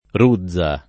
ruzza [ r 2zz a ] s. f.